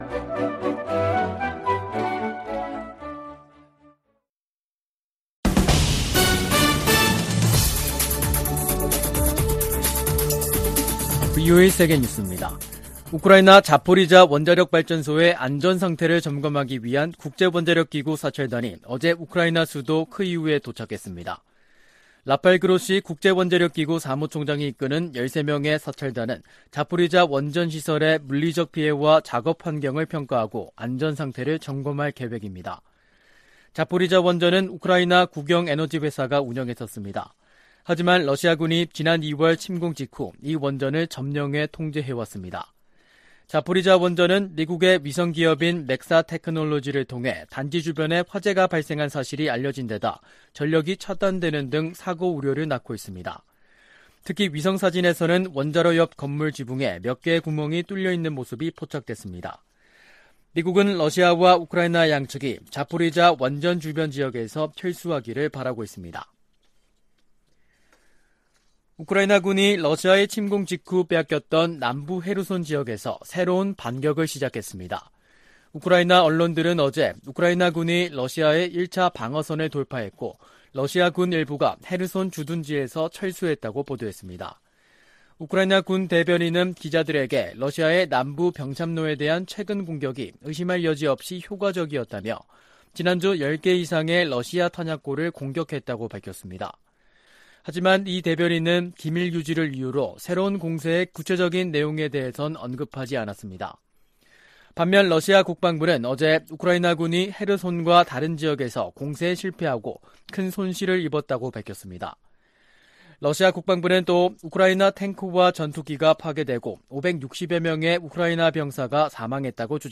VOA 한국어 간판 뉴스 프로그램 '뉴스 투데이', 2022년 8월 30일 3부 방송입니다. 미 국무부는 북한과의 대화 필요성을 재확인하면서도 도발에 대응하고 제재를 이행하겠다는 의지를 강조했습니다. 권영세 한국 통일부 장관은 ‘담대한 구상’ 제안에 북한이 호응할 것을 거듭 촉구했습니다. 미 의회 내에서는 북한이 핵실험을 강행할 경우 북한과 거래하는 중국 은행에 ‘세컨더리 제재’를 가해야 한다는 목소리가 높습니다.